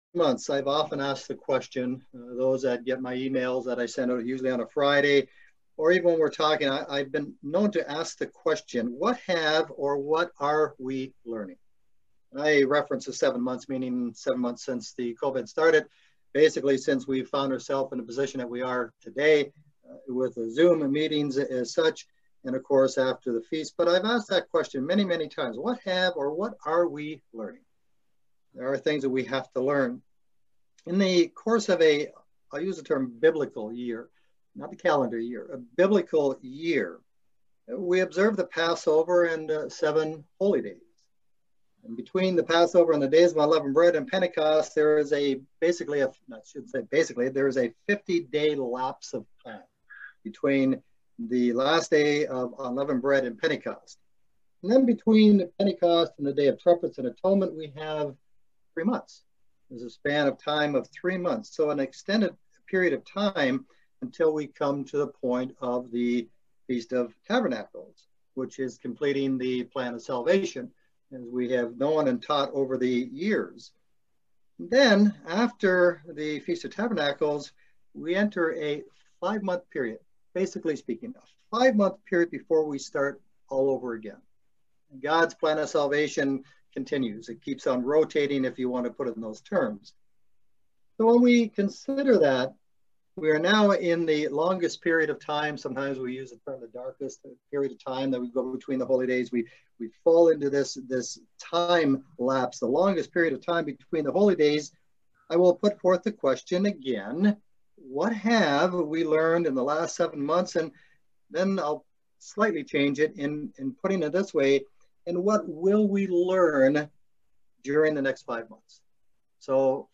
Join us for this eye opening Sermon about our Conduct and what God expects from us.